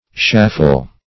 Search Result for " shaffle" : The Collaborative International Dictionary of English v.0.48: Shaffle \Shaf"fle\, v. i. [See Shuffle .] To hobble or limp; to shuffle.